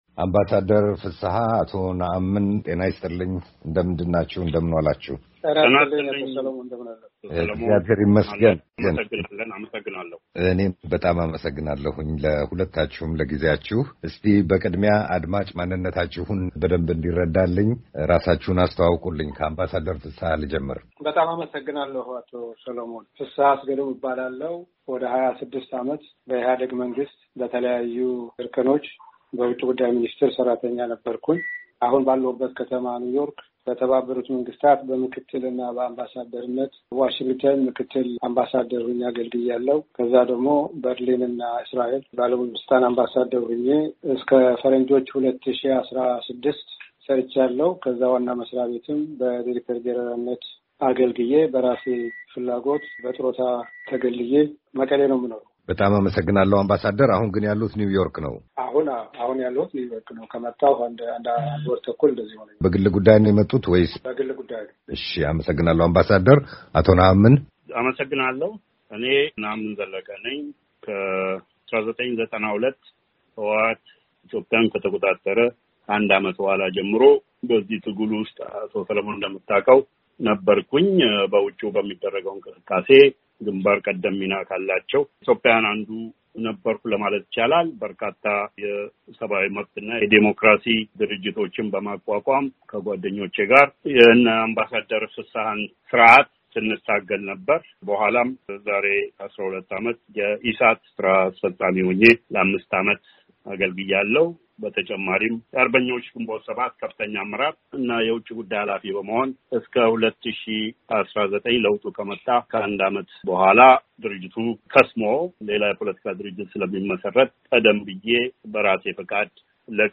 ክርክር በኢትዮጵያ የዛሬ ሁኔታ ላይ